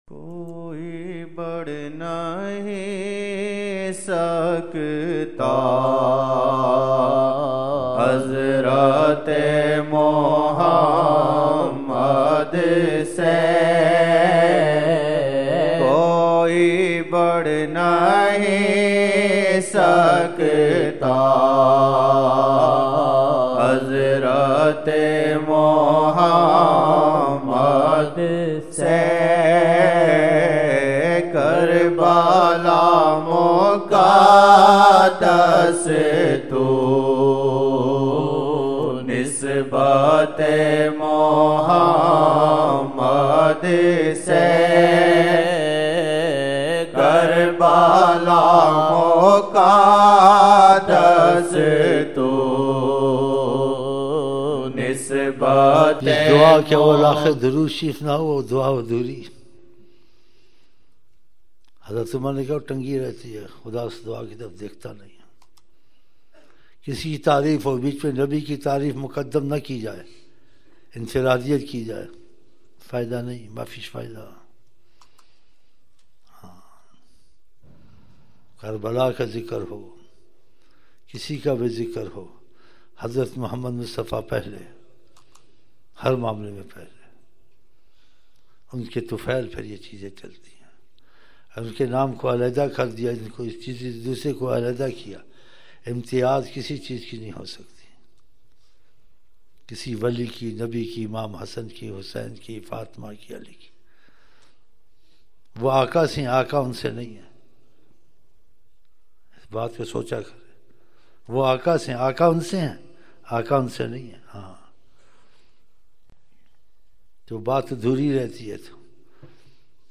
silsila ameenia | » Naat-e-Shareef